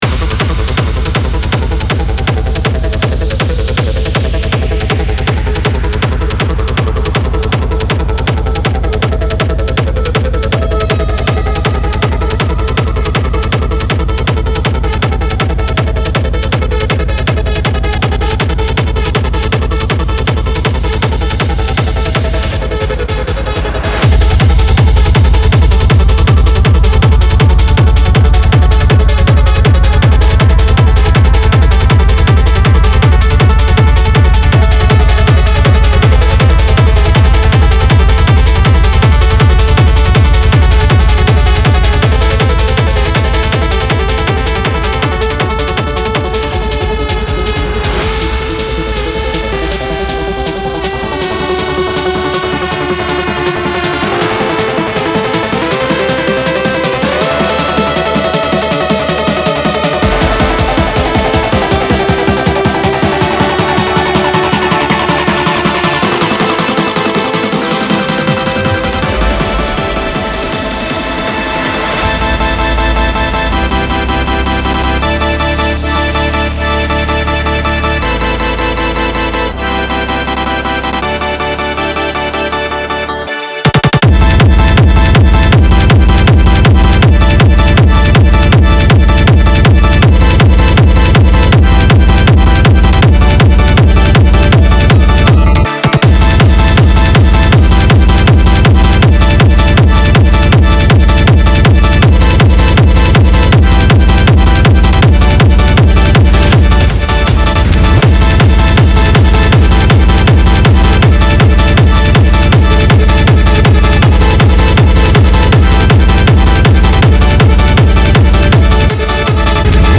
• Жанр: Hardstyle, Dance